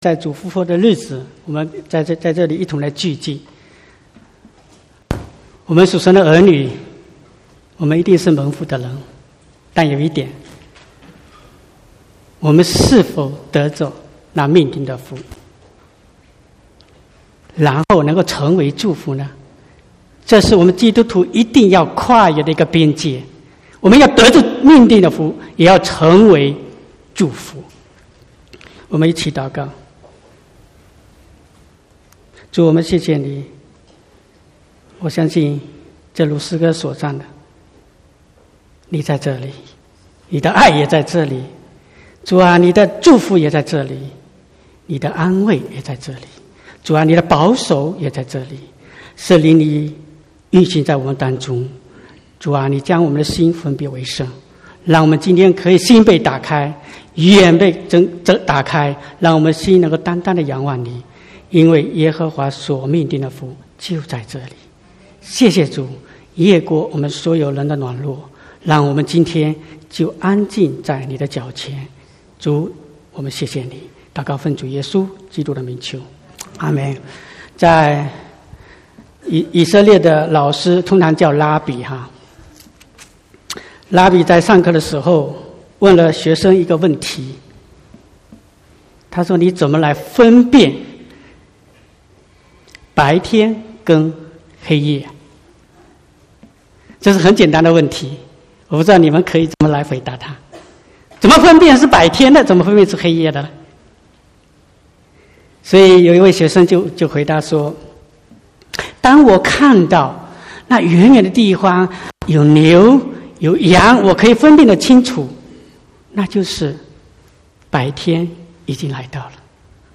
3/11/2019 國語堂講道